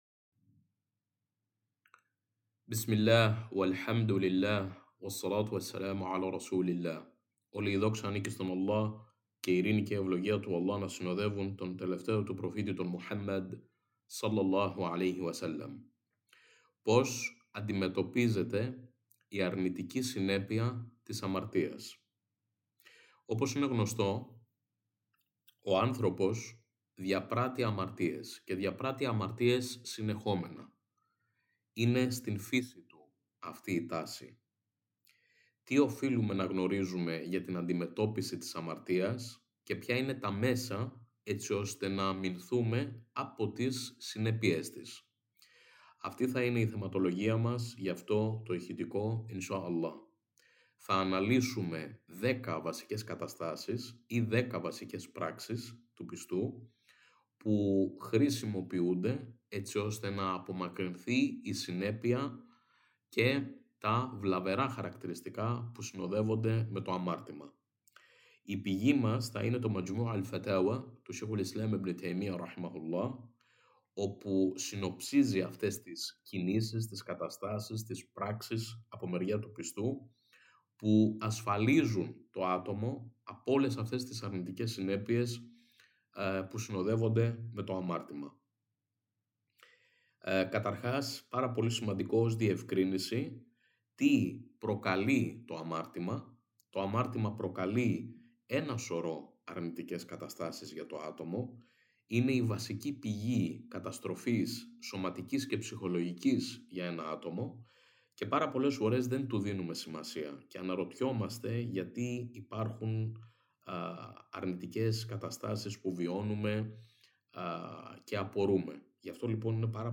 Τι οφείλουμε να γνωρίζουμε για την αντιμετώπιση της αμαρτίας και ποια είναι τα μέσα έτσι ώστε να αμυνθούμε από τις συνέπειες της. Στην παρακάτω ηχητική ομιλία αναλύονται δέκα βασικές πράξεις του πιστού που μπορεί να χρησιμοποιήσει έτσι ώστε να απομακρύνει τις συνέπειες και όλα τα βλαβερά χαρακτηριστικά που συνοδεύονται με το αμάρτημα.